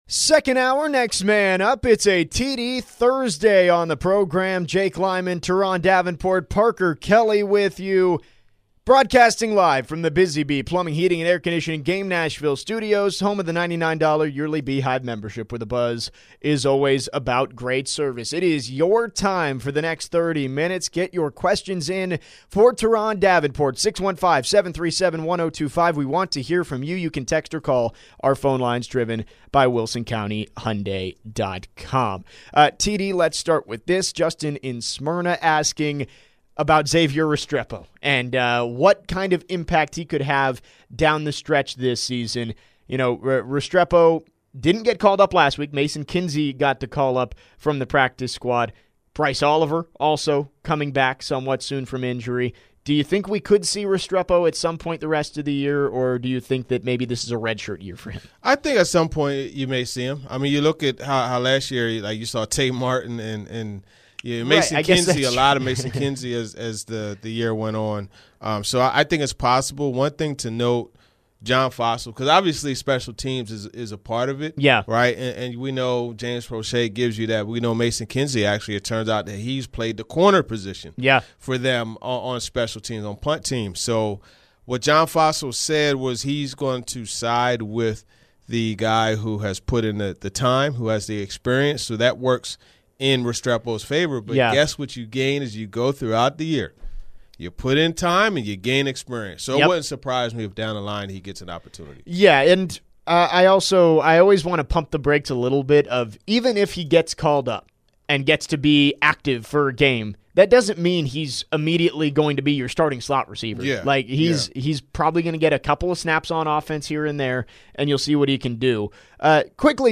Then, the guys analyze how the Titans' offensive line looked in their first game without Bill Callahan. Plus, where can JC Latham improve the rest of this season?